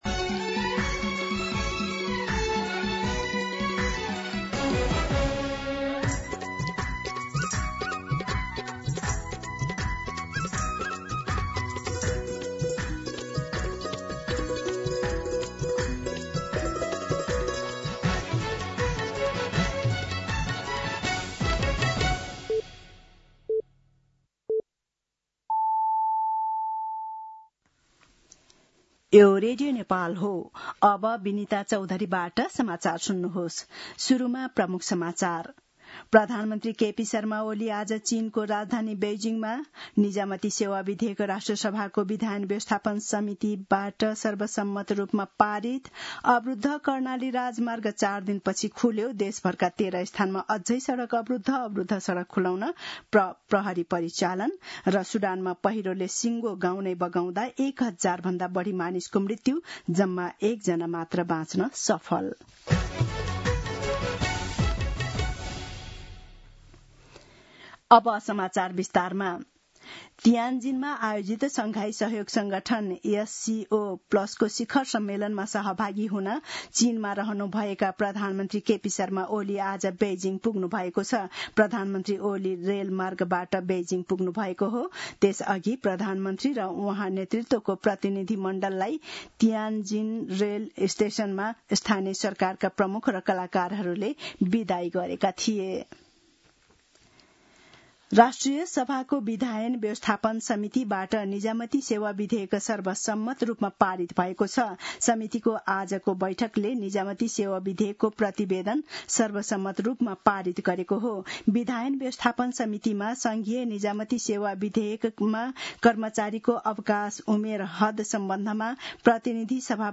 दिउँसो ३ बजेको नेपाली समाचार : १७ भदौ , २०८२
3pm-News.mp3